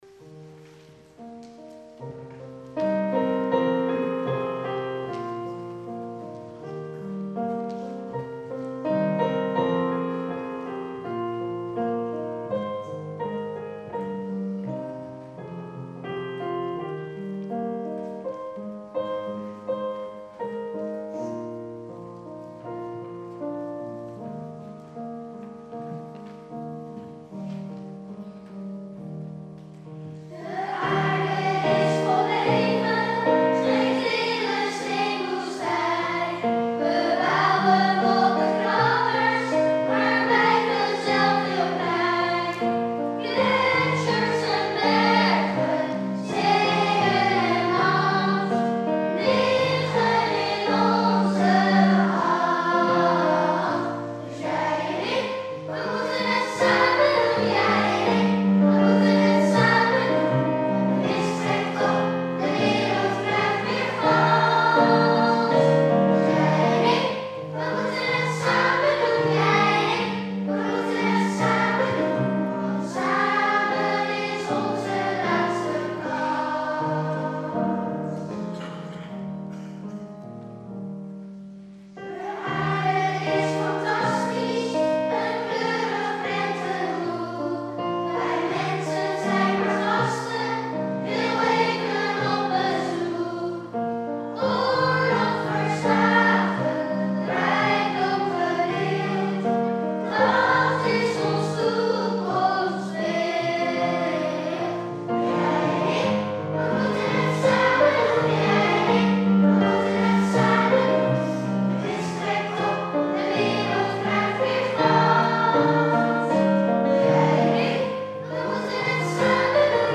Nicolaas kinderkoor